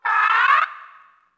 Faerie Dragon Yells
精灵龙叫声